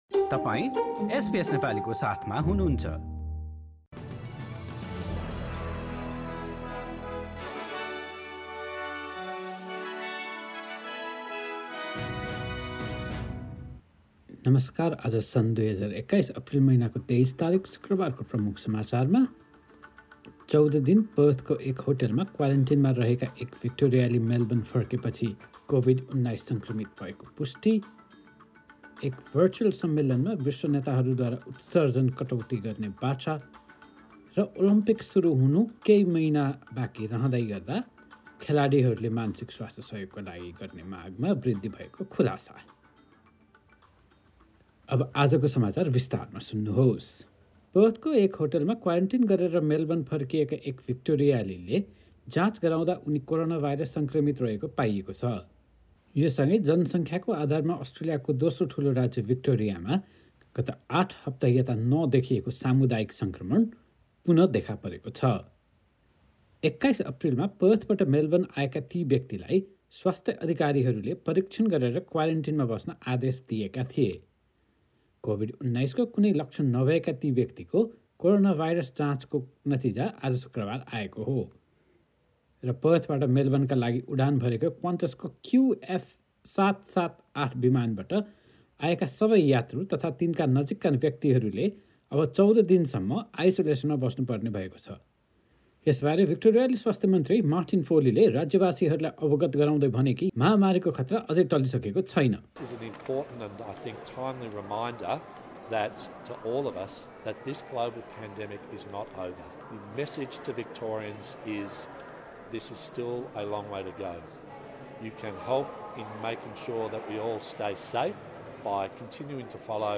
Listen to the latest news headline from Australia in Nepali